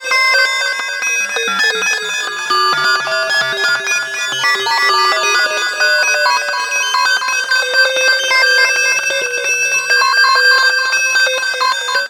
Modulating Bells.wav